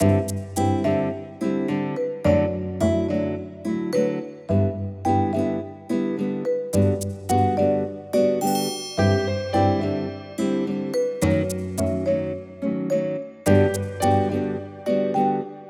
15秒BGM第34弾！ブラジルのボサノヴァ風のBGMです！
ループ：◎
BPM：107 キー：Cリディアン ジャンル：おしゃれ、あかるい 楽器：ベース、ピアノ、ギター、ストリングス